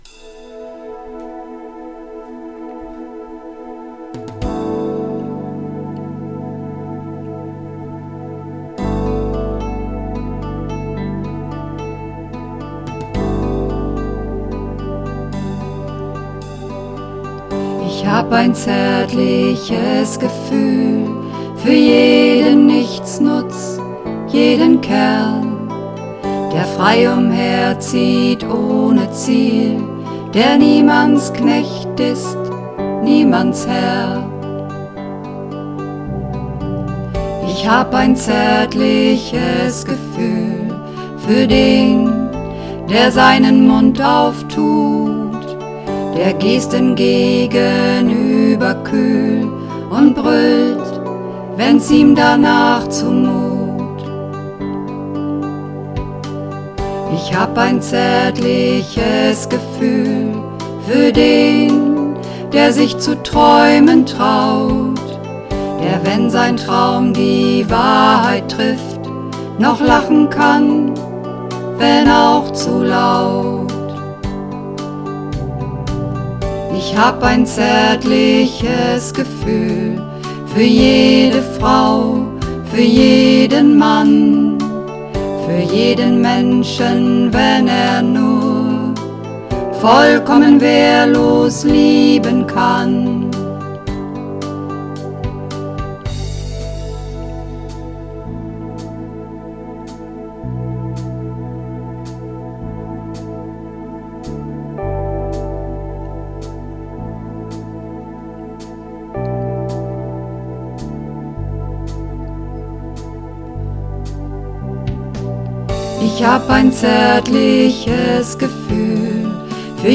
Ich_hab_ein_zaertliches_Gefuehl__4_Mehrstimmig.mp3